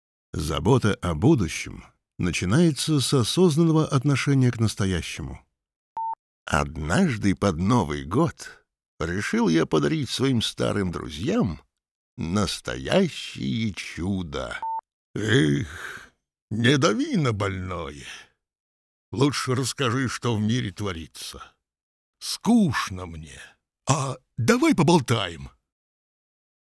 Тракт: Микрофон TLM 103,Sennheiser MKH 416-P48U3, карта YAMAHA 03,